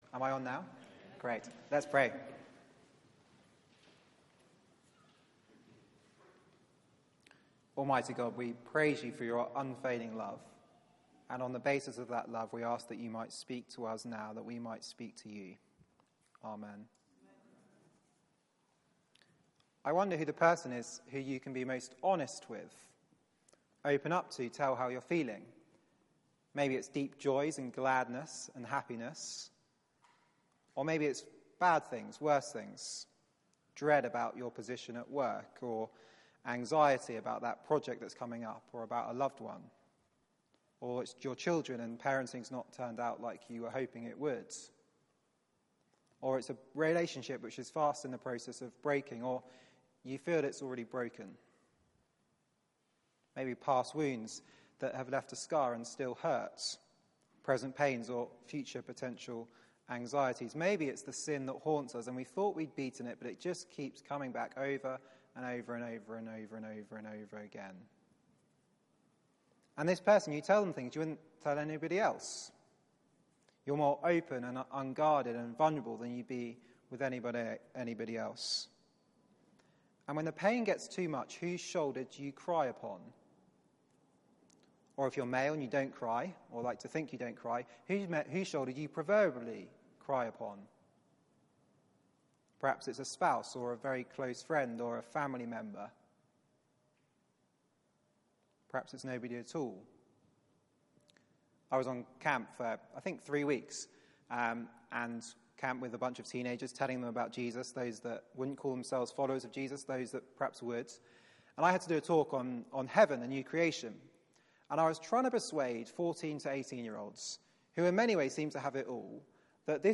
Media for 4pm Service on Sun 14th Aug 2016 16:00 Speaker
Psalm 6 Series: Summer Songs Theme: The God who delivers Sermon Search the media library There are recordings here going back several years.